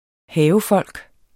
Udtale [ ˈhæːvəˌfʌlˀg ]